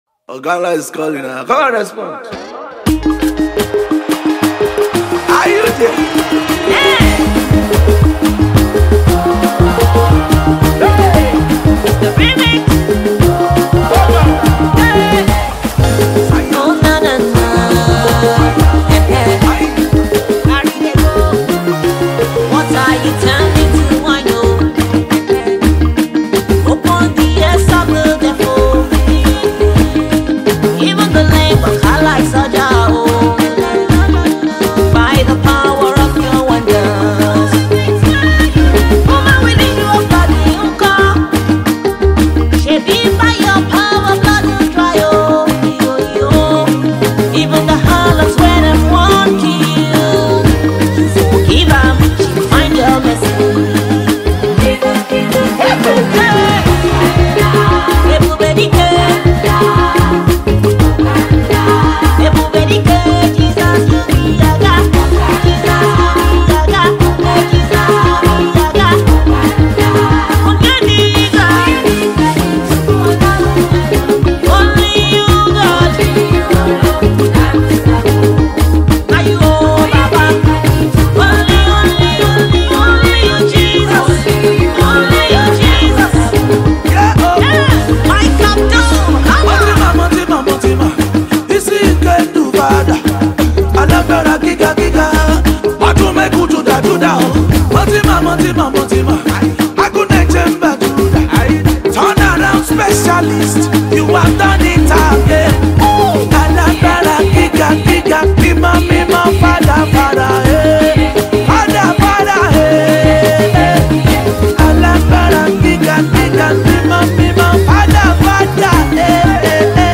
Nigerian Yoruba Fuji track
Yoruba Fuji Sounds